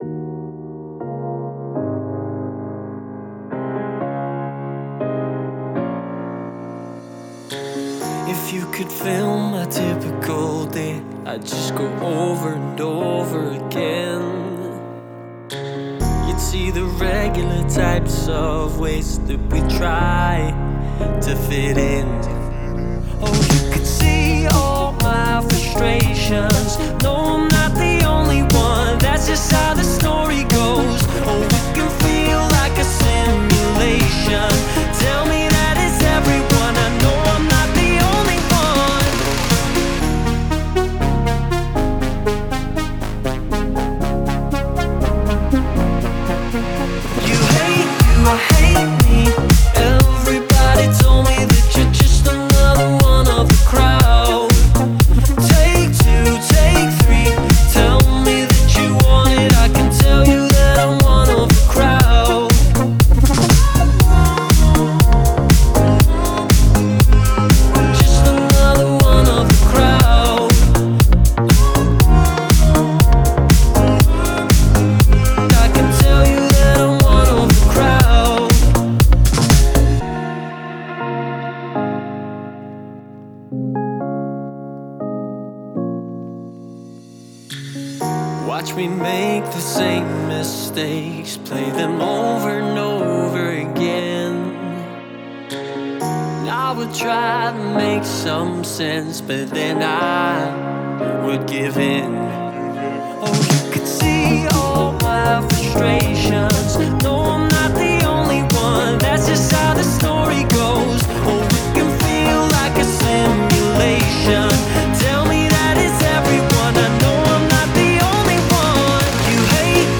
это энергичный трек в жанре электронной музыки